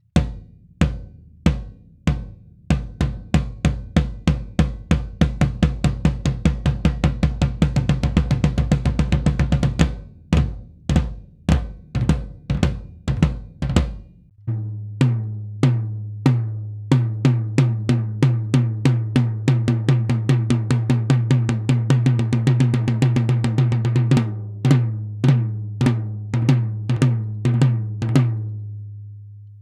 Schlagfell: Amba coated, Reso CS Black dot. Abstand zur Trommel: rund 10 cm
Beyerdynamics M 201 TG
sm57 und m201 heben den Anschlagsklick deutlich hervor, was mir ganz gut gefällt.
Die Trommeln klingen auch in Natur recht dumpf - wie früher eben.